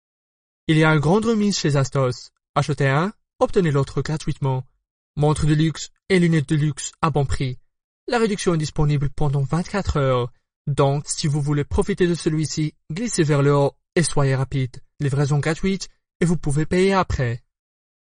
法语男5syntxe_外语_小语种_样音2.mp3